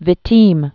(vĭ-tēm)